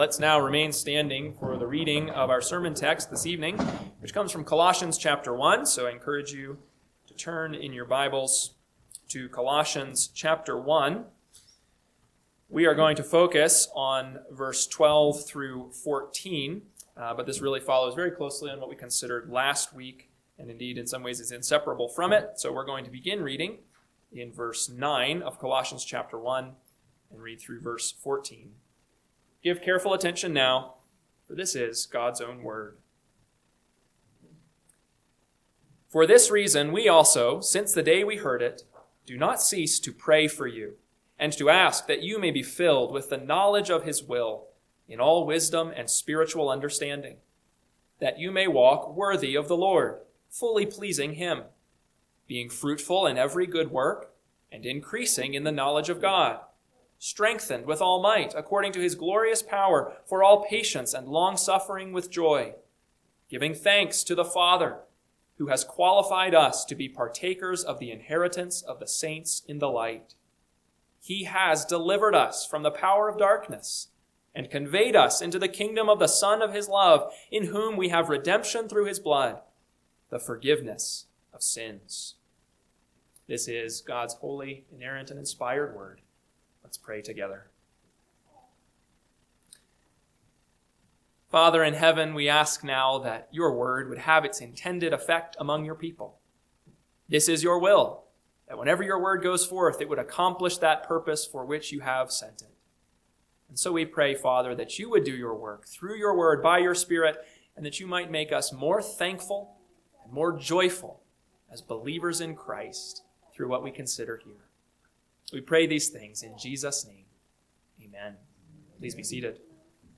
PM Sermon – 1/4/2026 – Colossians 1:12b-14 – Northwoods Sermons